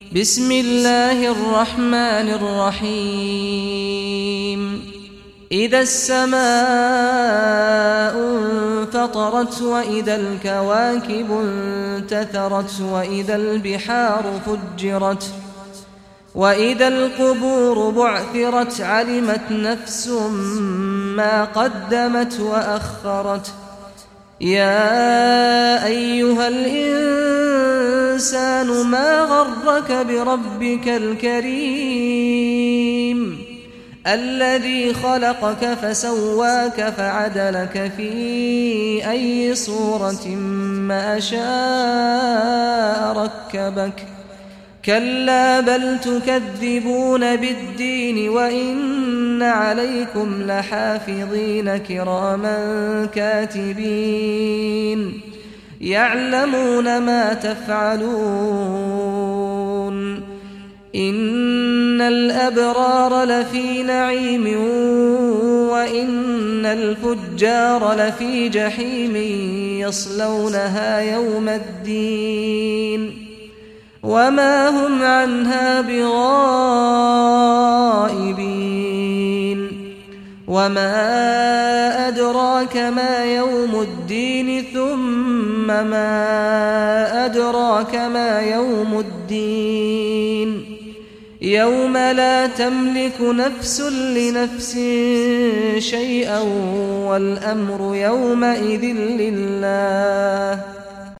Surah Al Infitar Recitation by Sheikh Saad Ghamdi
Surah Al Infitar, listen or play online mp3 tilawat / recitation in Arabic in the beautiful voice of Sheikh Saad al Ghamdi.